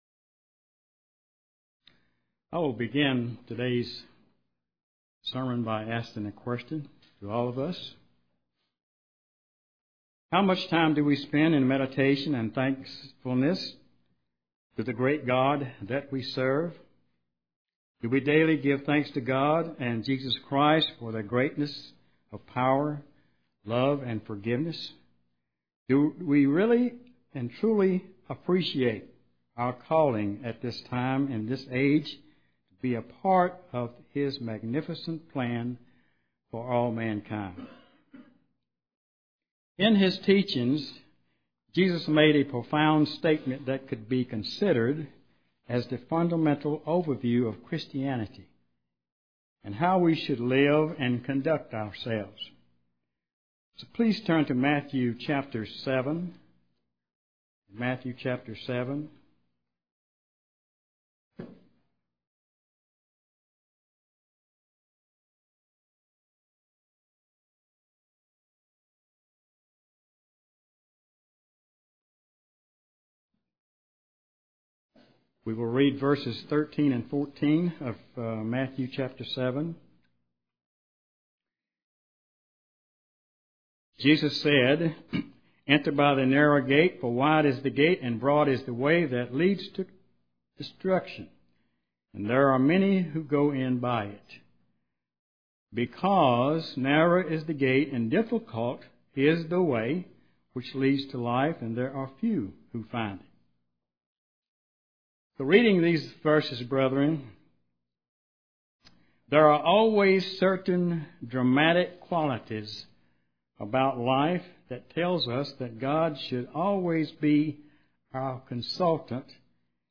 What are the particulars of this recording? Given in Charlotte, NC